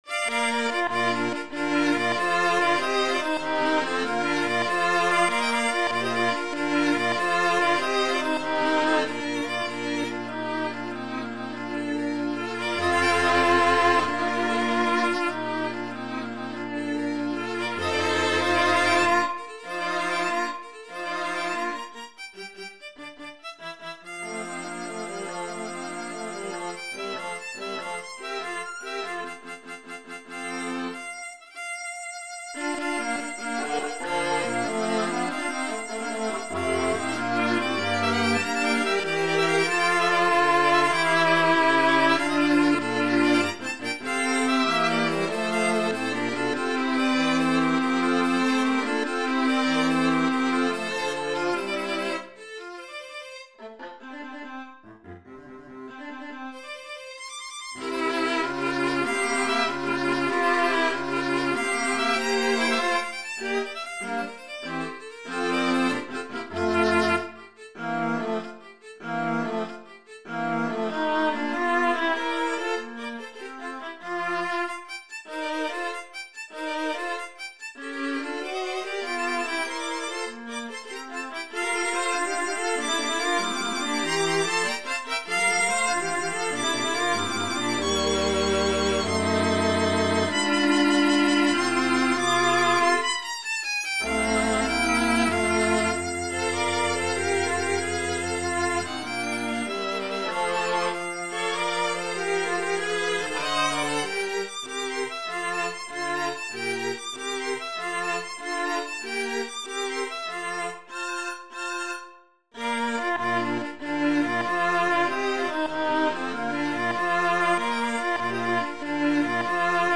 KV 458 String Quartet in B flat major "The Hunt" - Allegro vivace assai   [stream]